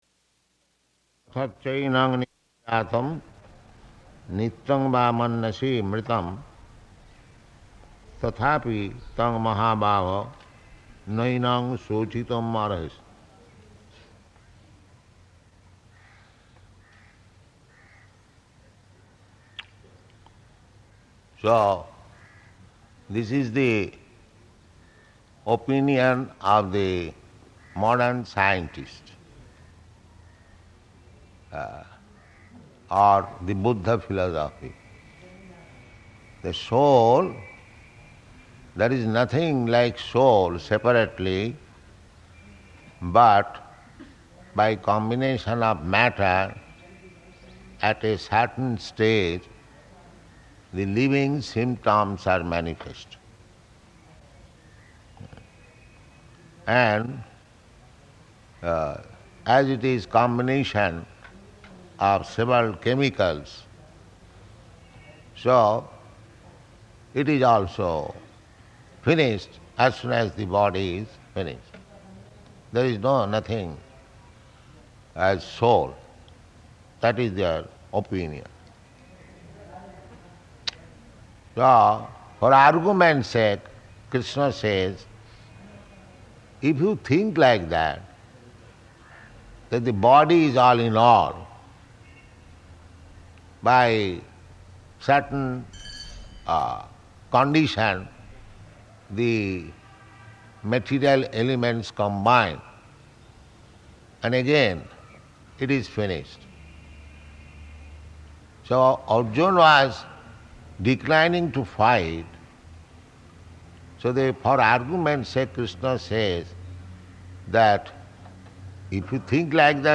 Type: Bhagavad-gita
Location: Hyderabad